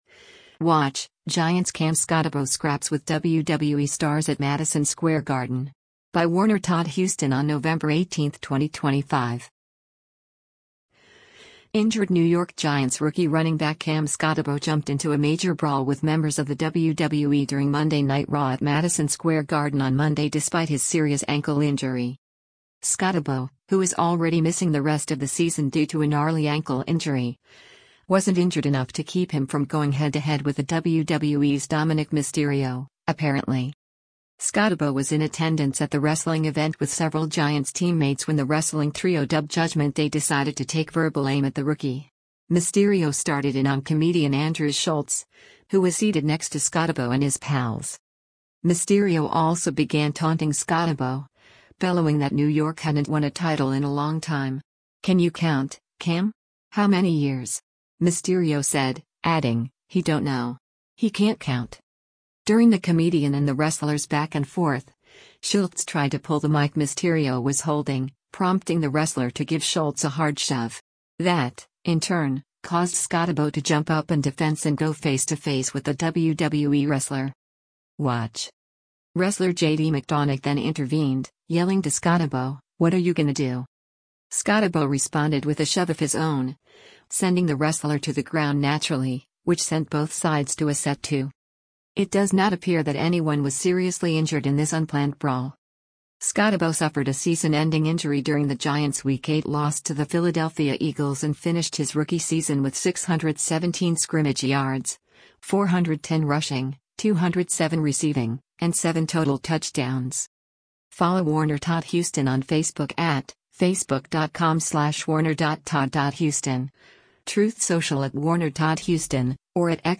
Injured New York Giants rookie running back Cam Skattebo jumped into a major brawl with members of the WWE during Monday Night Raw at Madison Square Garden on Monday despite his serious ankle injury.
Mysterio also began taunting Skattebo, bellowing that New York hadn’t won a title in a long time.
Wrestler JD McDonagh then intervened, yelling to Skattebo, “What are you gonna do?”